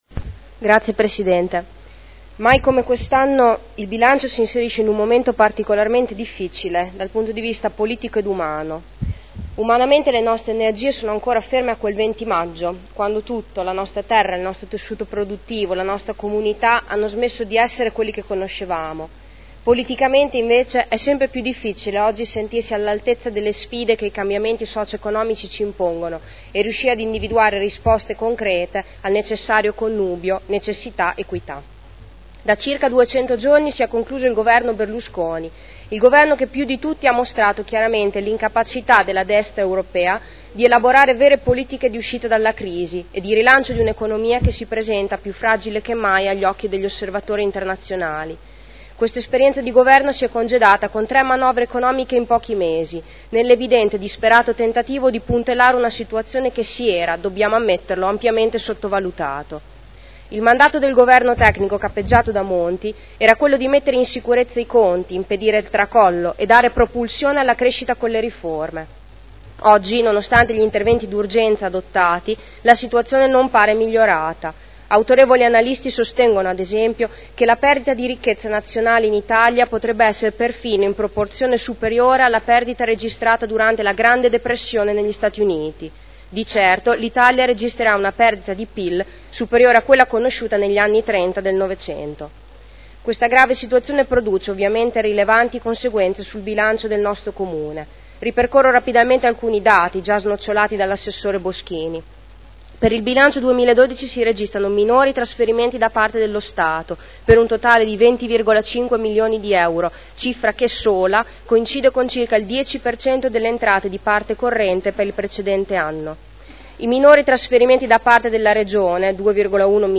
Giulia Morini — Sito Audio Consiglio Comunale